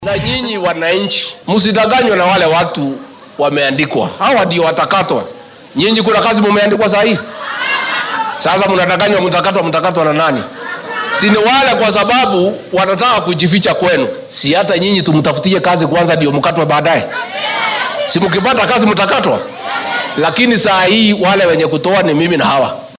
Madaxweyne ku xigeenka dalka Rigathi Gachagua ayaa sheegay in dowladda dhexe ay ka go’an tahay inay hoos u dhigto qiimaha adeegga korontada. Arrintan ayuu ka hadlay xilli uu ismaamulka Nakuru ka dhagax dhigay dhismaha mashuurca soo saaridda korontada ee Menengai.